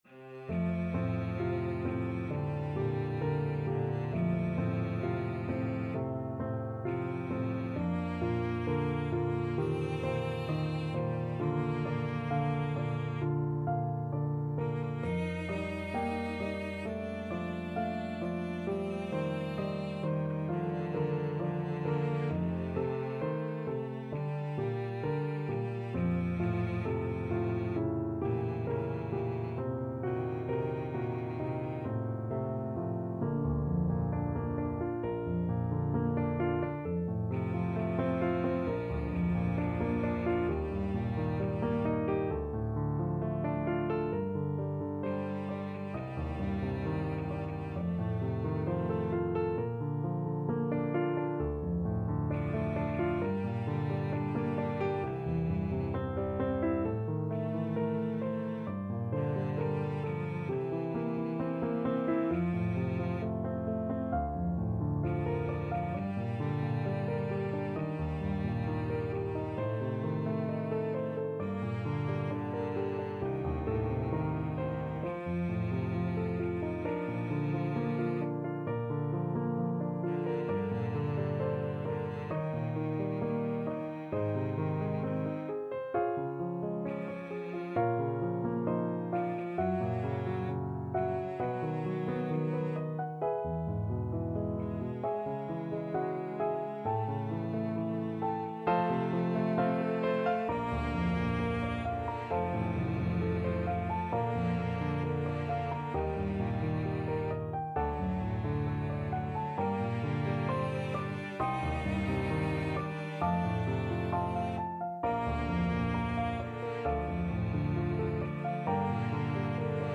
4/4 (View more 4/4 Music)
Andante ( = 66 )
Cello Duet  (View more Intermediate Cello Duet Music)
Classical (View more Classical Cello Duet Music)